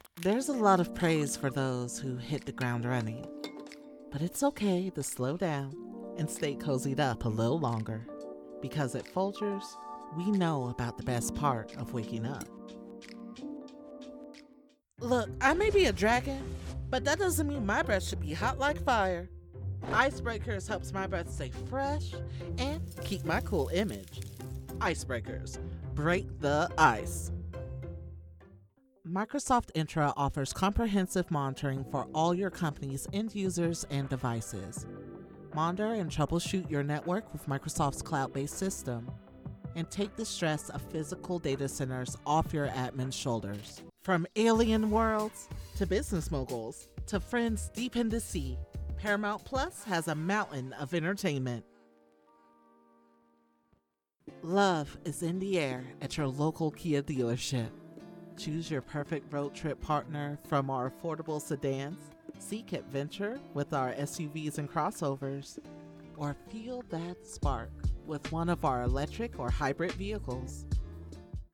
American Voice Over Talent
Adult (30-50) | Older Sound (50+)
The Voice Realm represents professional and affordable American and Canadian voice talent with authentic North American accents suited to international voice castings, from small jobs to international campaigns.
Our voice over talent record in their professional studios, so you save money!